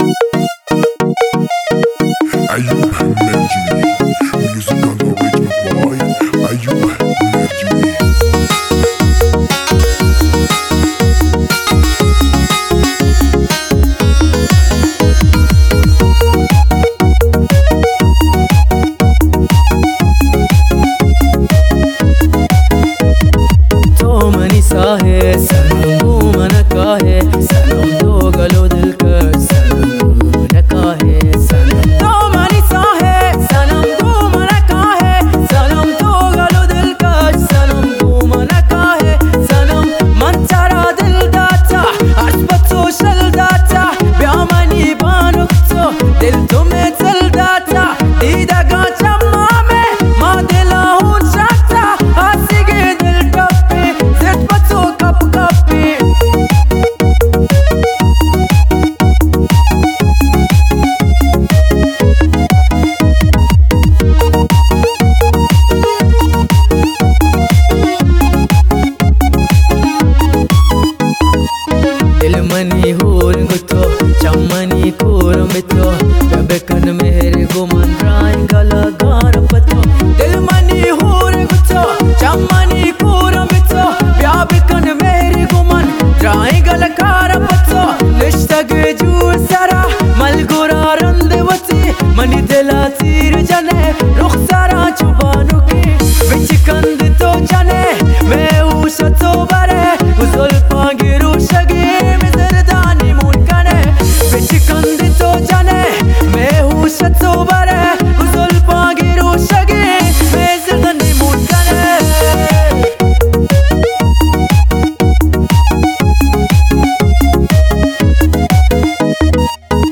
محلی
اهنگ ایرانی